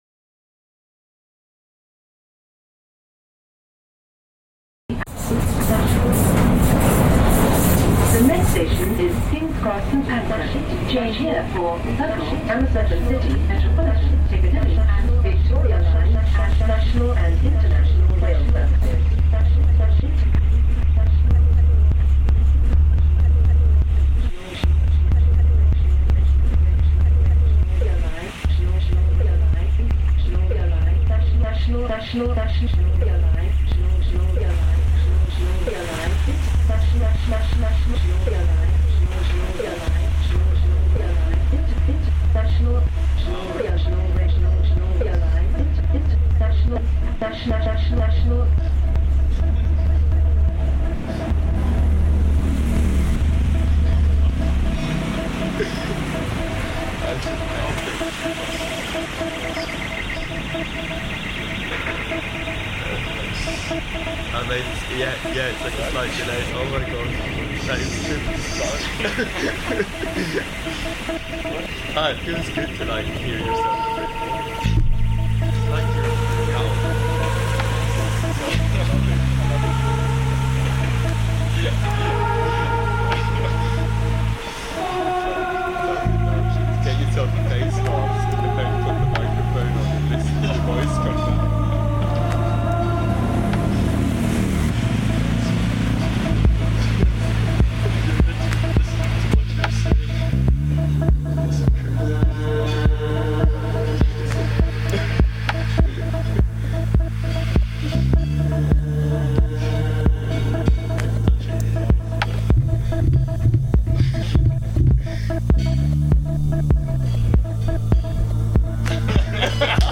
In a world where noise pollution has become the second-largest health threat in urban areas, this 30-minute soundscape shows how constant noise wears on our minds and bodies, contributing to stress, anxiety, and learned helplessness.
By blending overwhelming noise with pockets of stillness, the work encourages a return to the present moment - a chance to hear what’s often ignored. It challenges us to slow down and listen with intention.